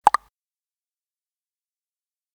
Звук оповещения о новом сообщении ВКонтакте на компьютере